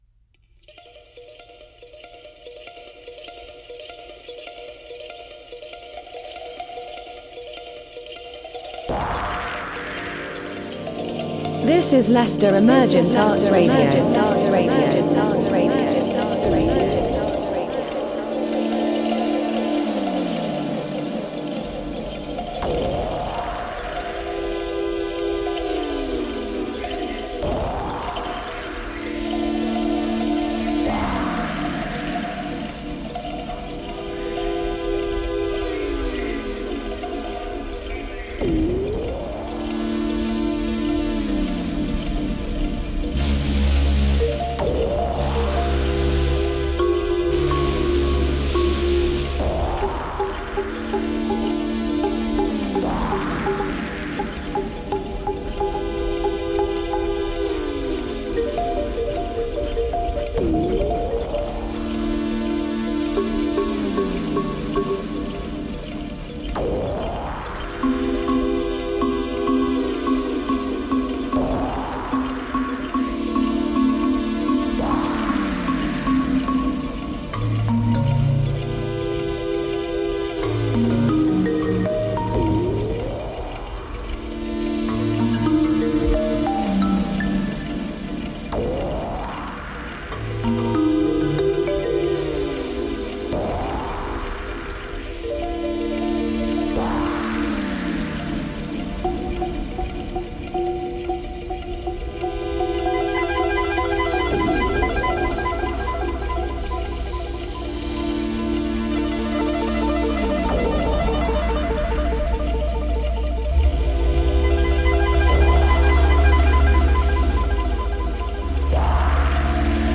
Inspired by Carl Jung’s concept of Enantiodromia, where extreme opposites transform into one another, this episode features a curated selection of music that reflects this dynamic interplay.